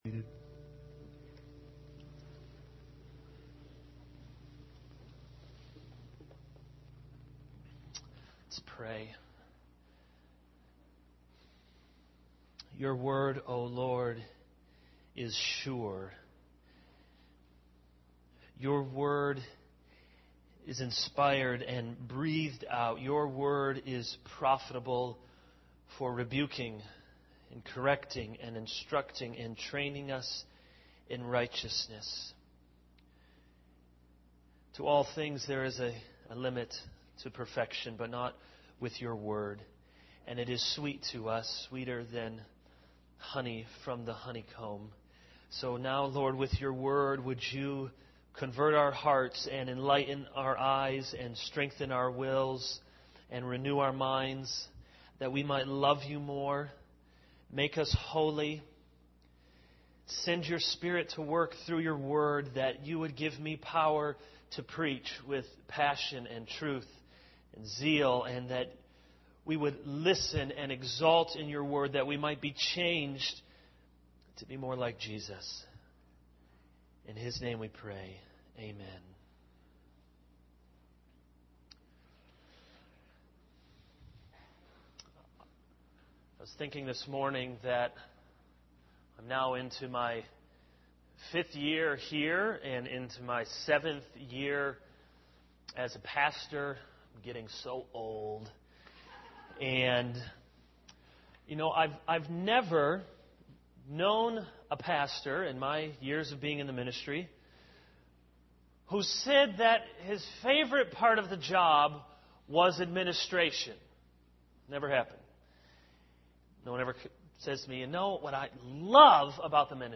This is a sermon on 2 Corinthians 8:16-9:5.